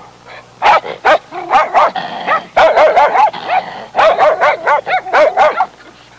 Dogfight Sound Effect
Download a high-quality dogfight sound effect.
dogfight.mp3